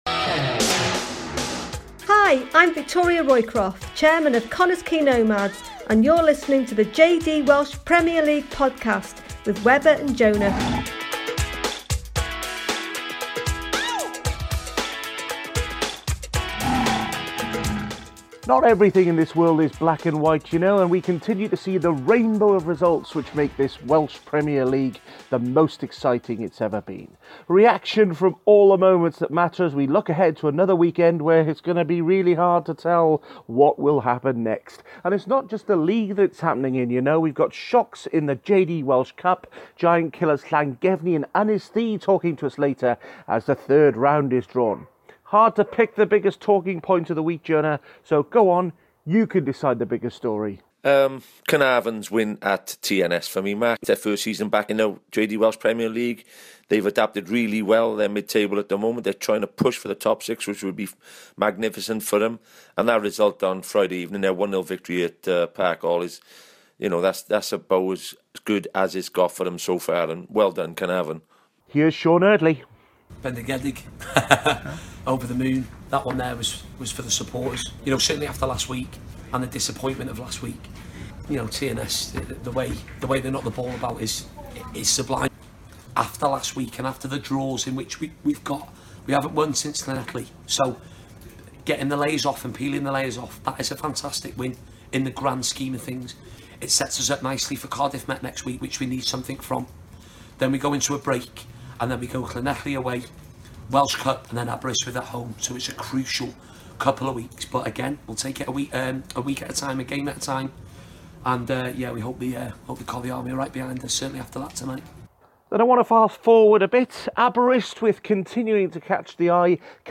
Manager interviews from Ynysddu to Nomads.